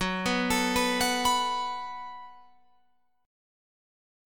F#mbb5 chord